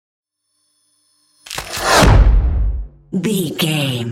Dramatic whoosh to hit trailer
Sound Effects
Fast paced
In-crescendo
Atonal
dark
driving
intense
tension
woosh to hit